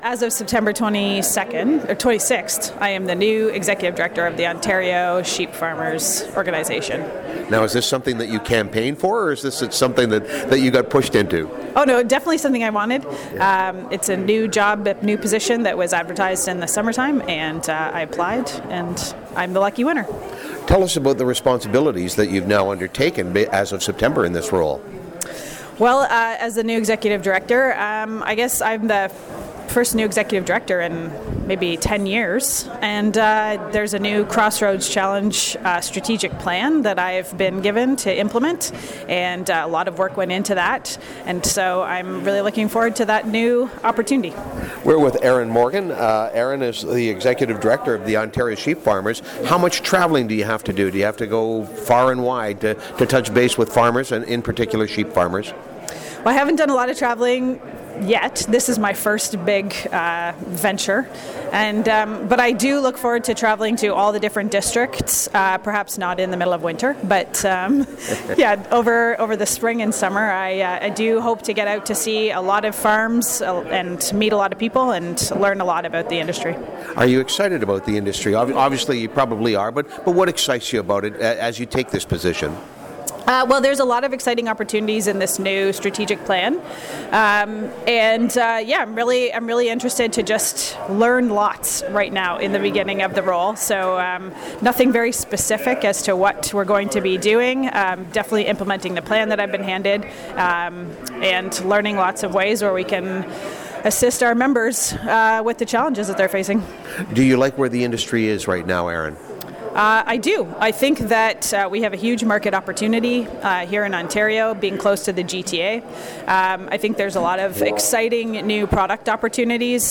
The 57th annual Grey Bruce Farmers Week is being staged at the Elmwood Community Centre